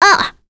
Leo-Vox_Damage_02.wav